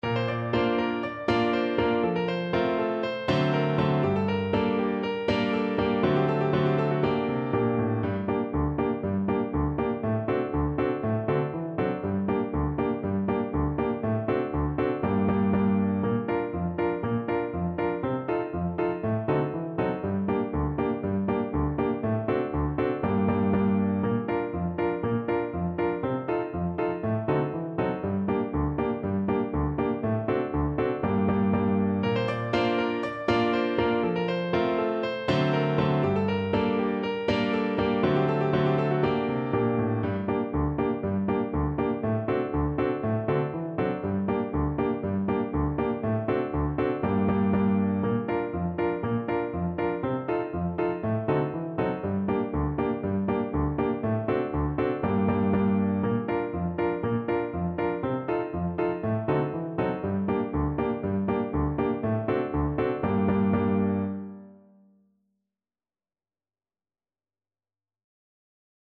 4/4 (View more 4/4 Music)
Allegro (View more music marked Allegro)
Traditional (View more Traditional French Horn Music)